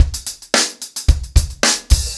TupidCow-110BPM.19.wav